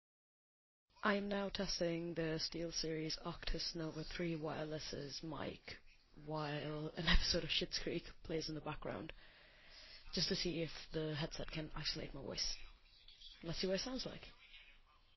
Затем я включил на фоне эпизод сериала «Шиттс Крик» и снова записал свой голос, чтобы проверить, изолирует ли гарнитура мой голос. Учтите, что эпизод проигрывался на 75% громкости на моем смартфоне, который я держал в руке. Как вы можете слышать в клипе выше, микрофон с шумоподавлением хорошо справился с подавлением звука эпизода, и меня по-прежнему слышно громко и четко — идеально!